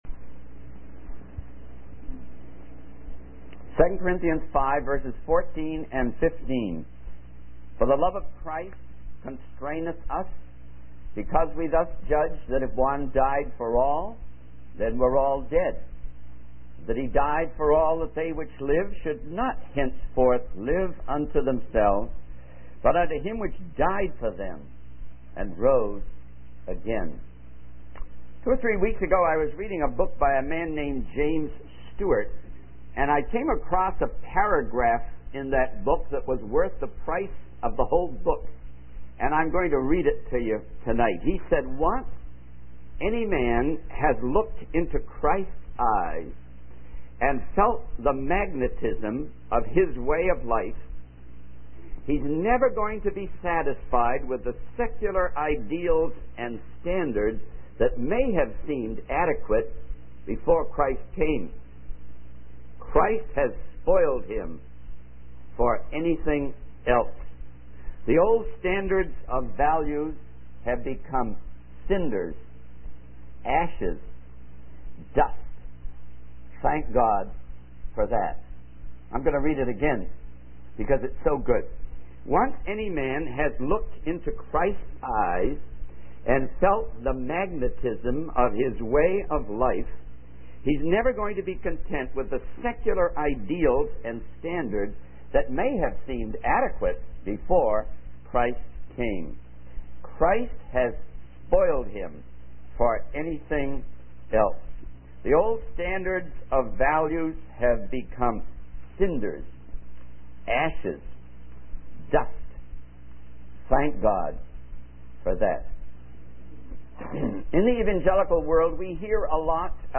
In this sermon, the speaker discusses the importance of having a vision and being ready to fully surrender to the Lord. He emphasizes that life is meaningless unless one is willing to give everything to God.